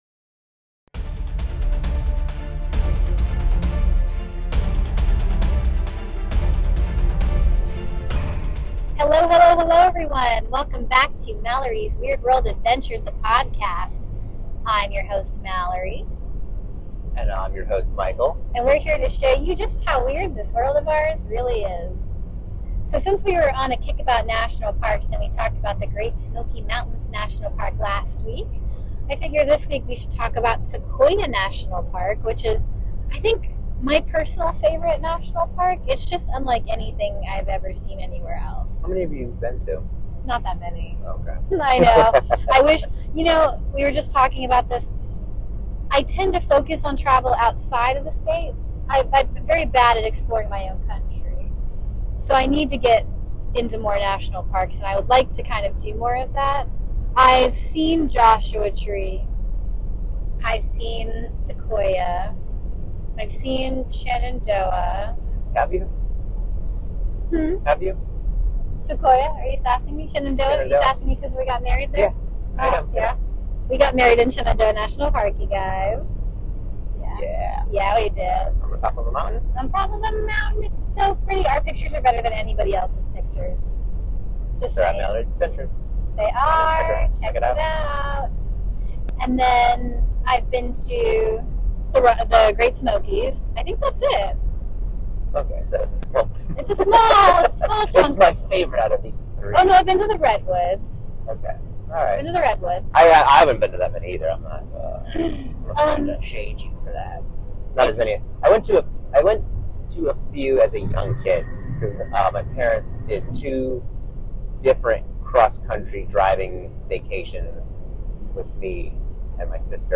From the big trees to Tokopah Falls, learn all about the best things to do in Sequoia National Park. (And we apologize in advance for the distressed audio. We recorded this while actively on the go, and we learned from our mistakes with that.)